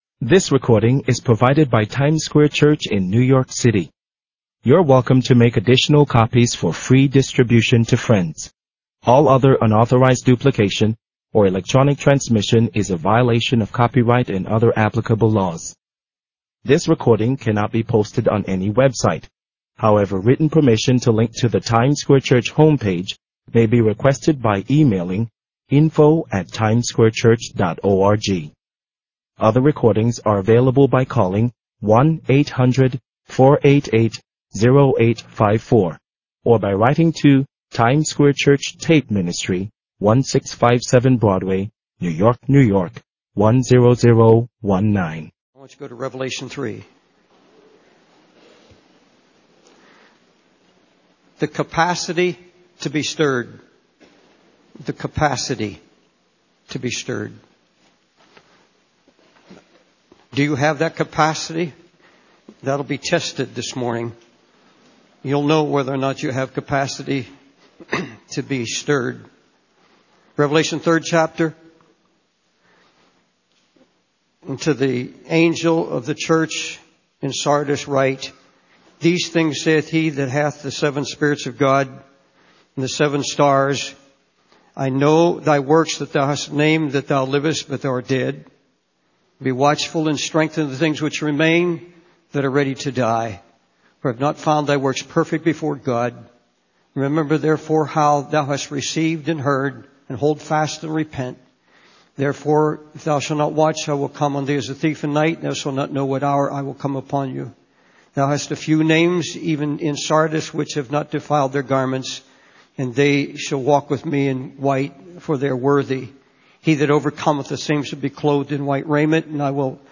In this sermon, the speaker emphasizes the importance of attending church regularly for spiritual health and protection against the negative influences of the world.